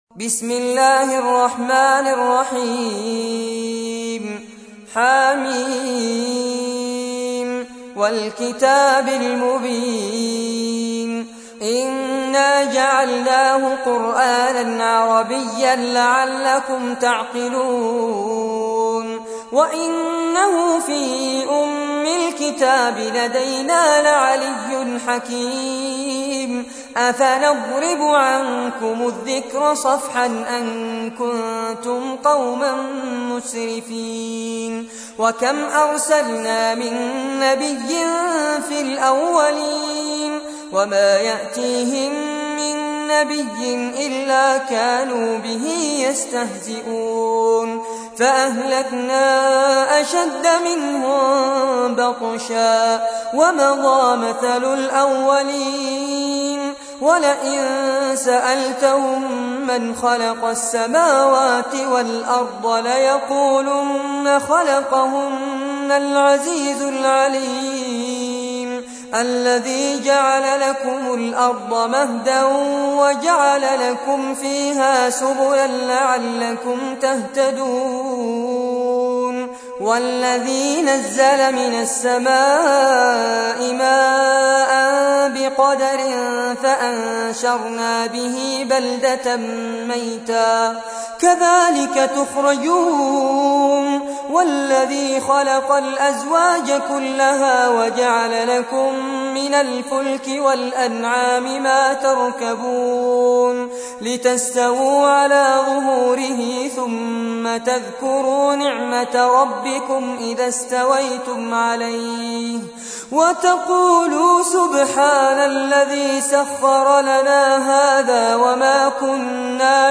تحميل : 43. سورة الزخرف / القارئ فارس عباد / القرآن الكريم / موقع يا حسين